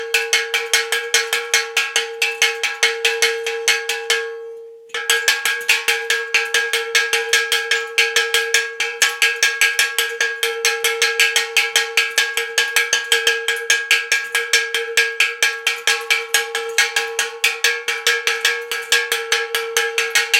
Kovový zvonček 17x26cm